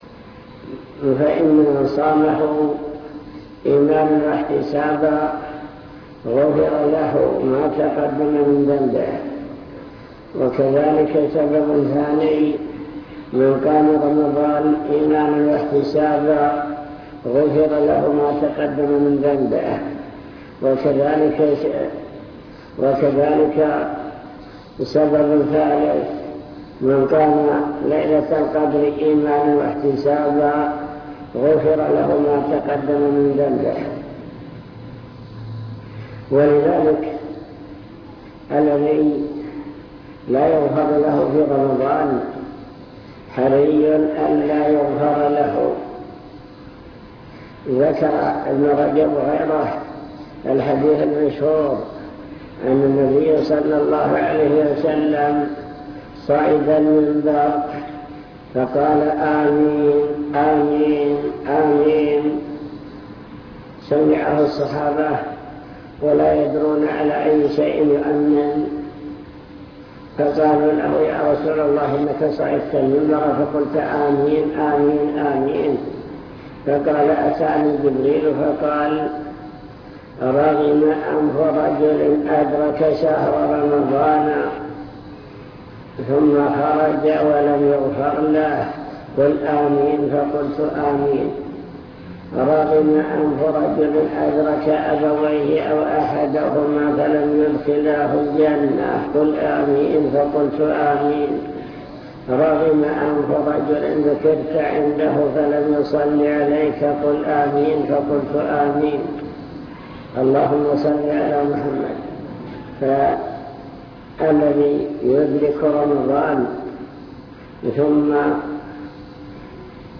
المكتبة الصوتية  تسجيلات - محاضرات ودروس  مجموعة محاضرات ودروس عن رمضان كيفية استقبال شهر رمضان